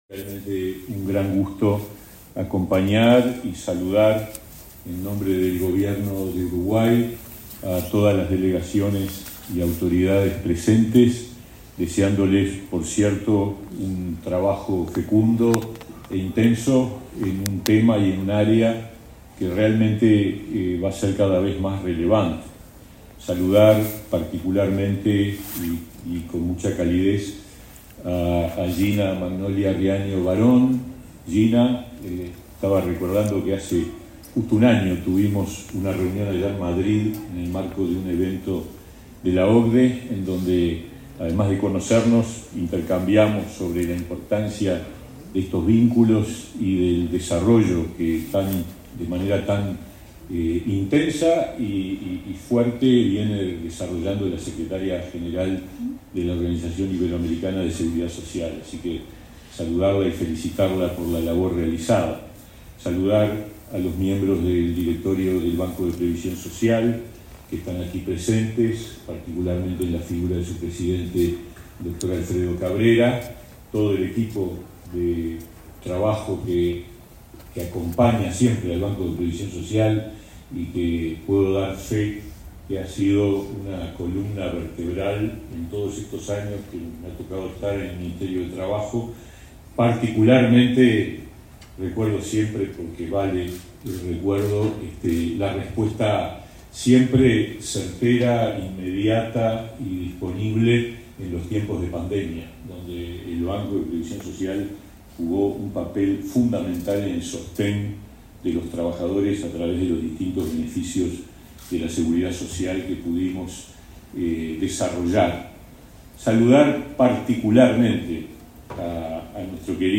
Palabras del ministro de Trabajo, Pablo Mieres
El ministro de Trabajo, Pablo Mieres, participó de la apertura de la XIV reunión del Comité Técnico Administrativo del Convenio Multilateral